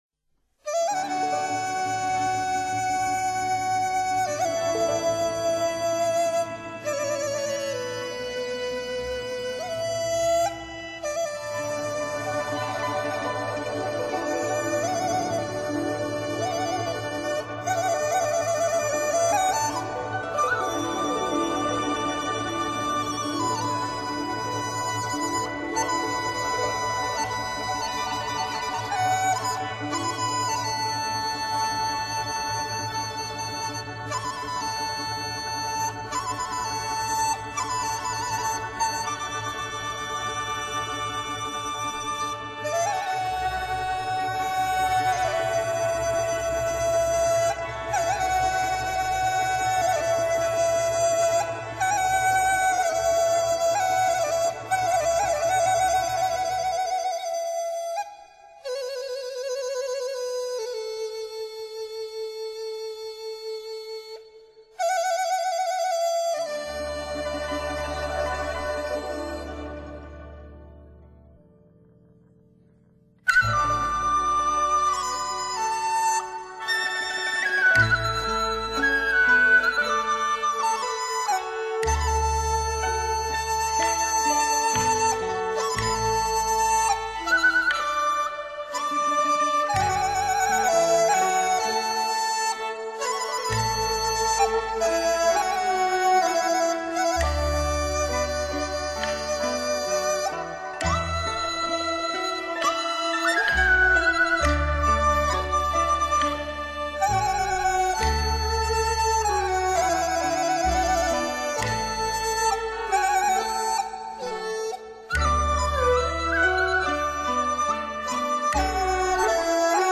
笛独奏 河北民歌